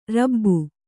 ♪ rabbu